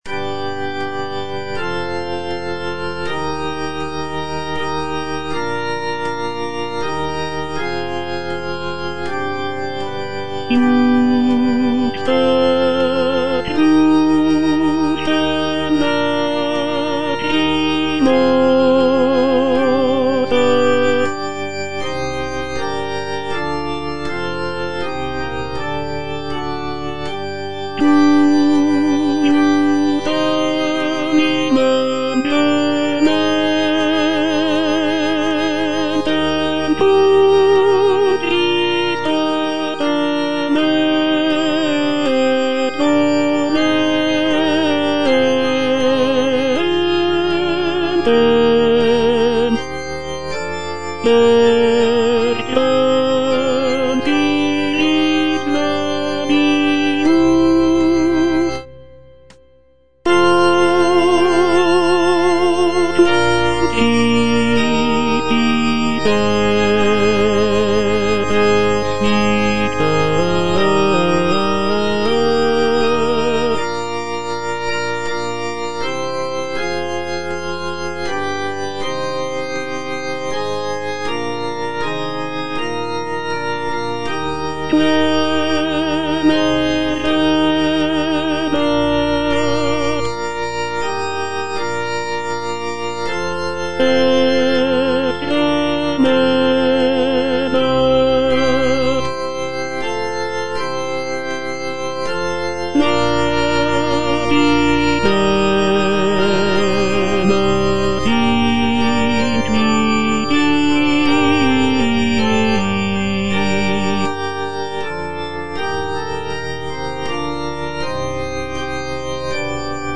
G.P. DA PALESTRINA - STABAT MATER Stabat Mater dolorosa (tenor II) (Voice with metronome) Ads stop: auto-stop
sacred choral work